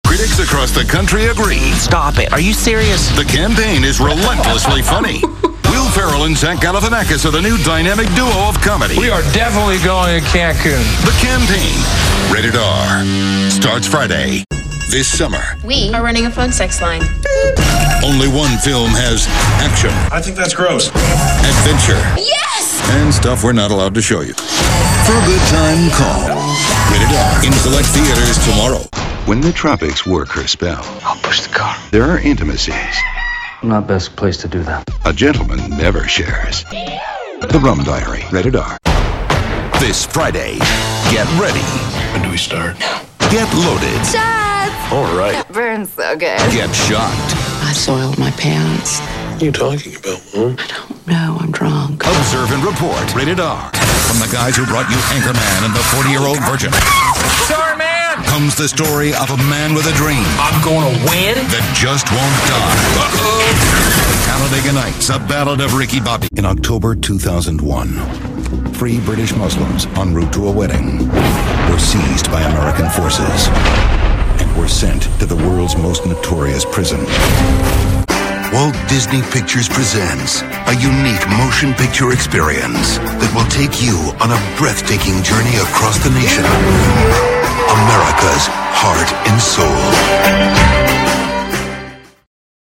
Native speaker Mężczyzna 50 lat +
Jeden z najbardziej znanych amerykańskich głosów.
Nagranie lektorskie
BillRatner_Movie_Trailers.mp3